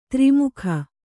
♪ tri mukha